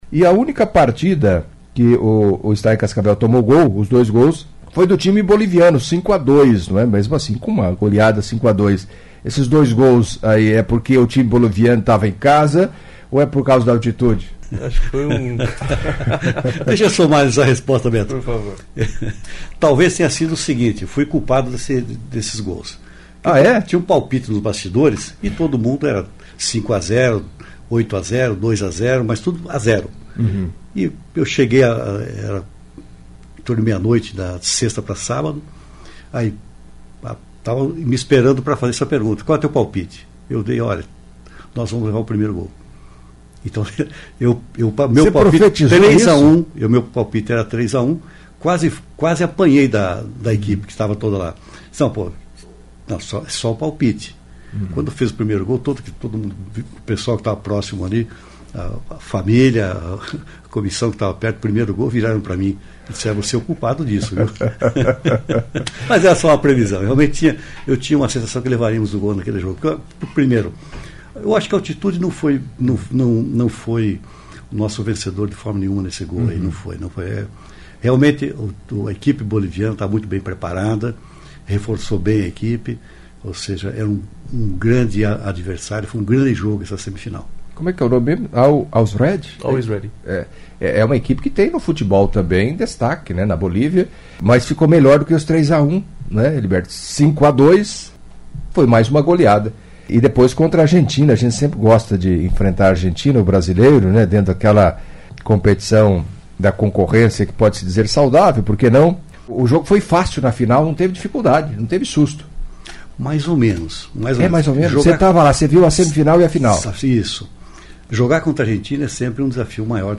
Em entrevista à CBN Cascavel nesta quinta-feira (01)